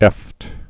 (ĕft)